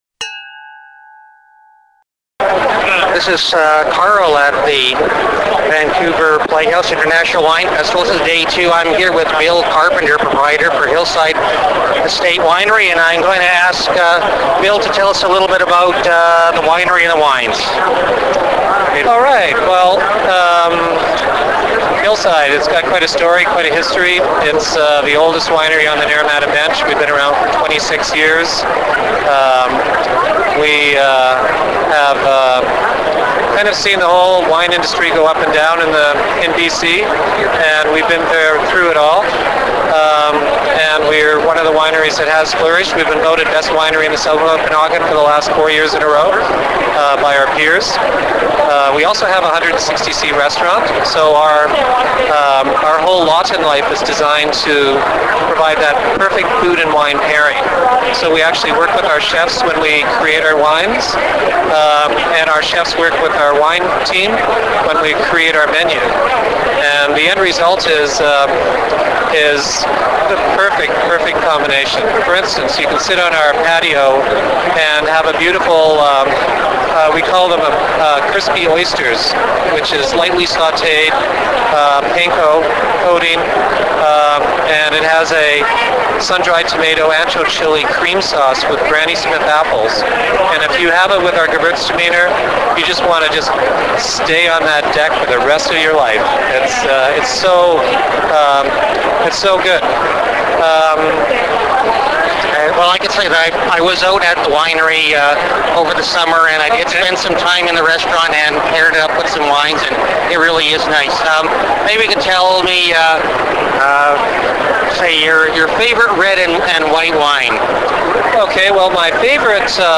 MyWinePa Hillside Estate Winery 2009 interview